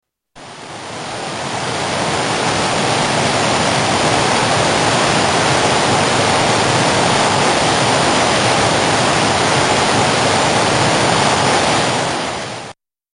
small waterfall
Tags: South America journey